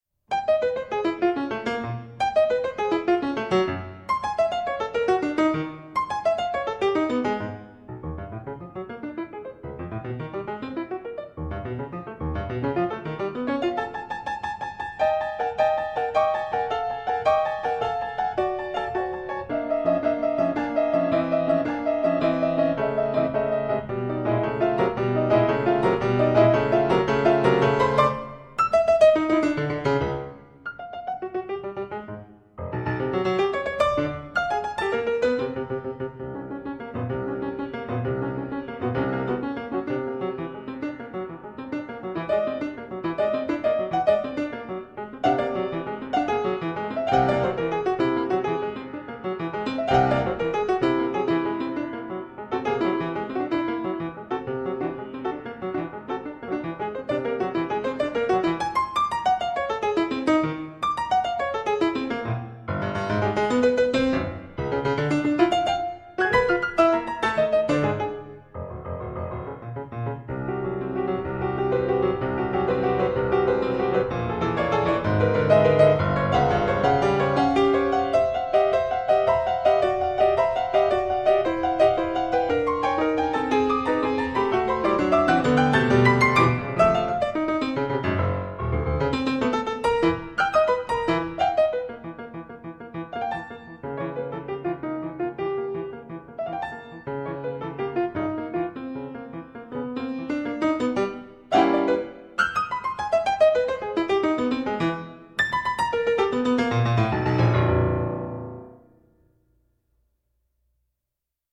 Harmonika